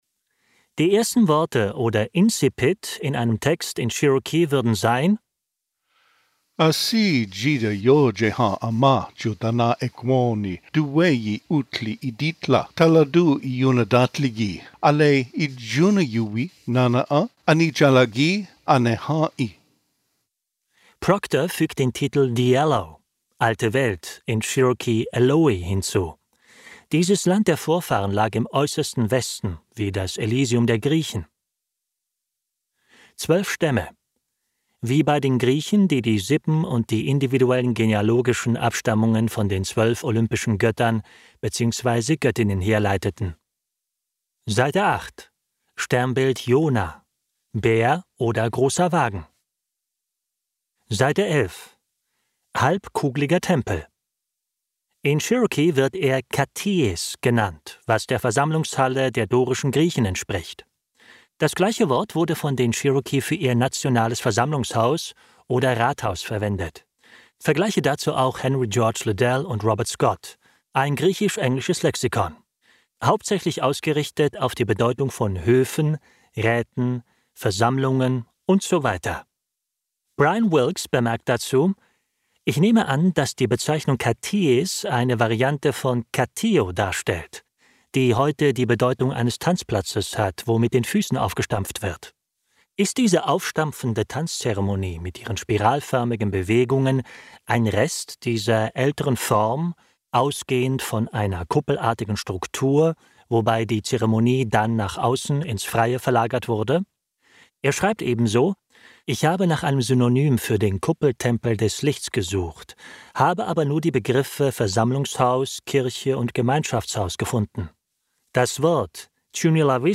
Male
Adult (30-50)
Voice tone: middle, strong
My voice is: confident, characterful, clear, playful, friendly, down to earth, engaging, warm, emotional, crazy, freaky, true.
Audiobooks